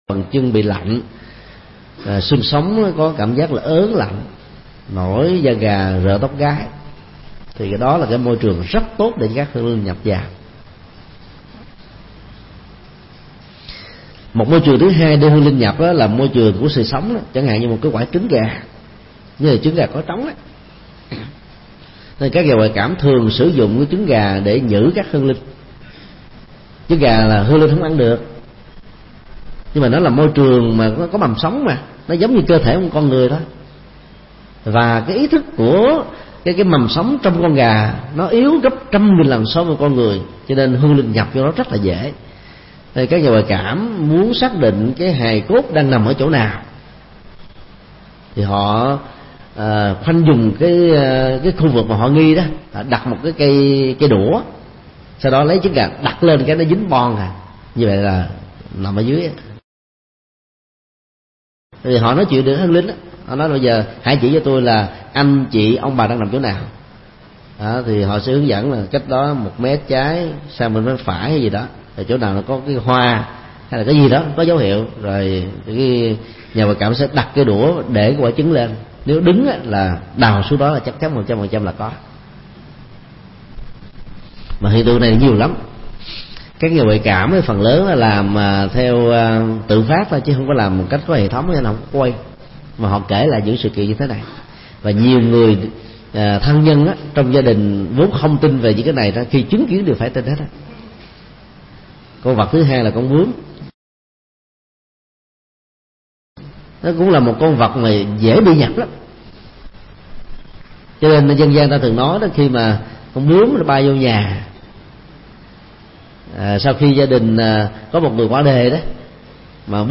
Tải mp3 Trị bệnh ma nhập – phần 2/2 – thầy Thích Nhật Từ thuyết pháp
Nghe Thầy Thích Nhật Từ chia sẻ mp3 đề tài Trị bệnh ma nhập – phần 2/2 tại trường hạ TX Trung Tâm, ngày 23 tháng 07 năm 2010.